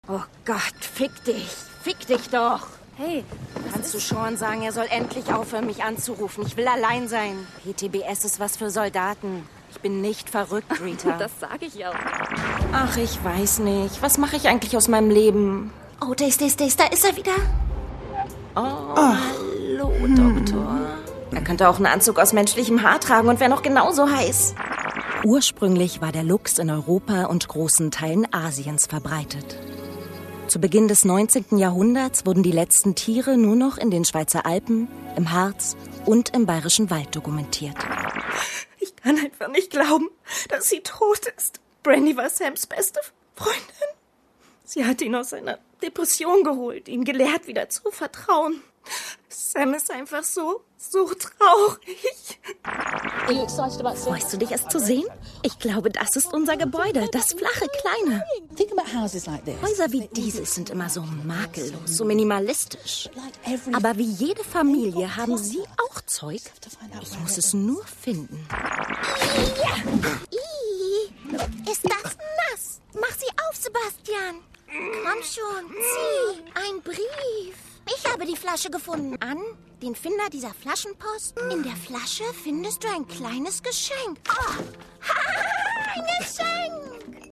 Meine Stimme ist frisch und jung. Seriös und warm. Und manchmal lasziv.
Sprechprobe: Sonstiges (Muttersprache):
My voice is fresh and young. Serious and warm. And sometimes lascivious.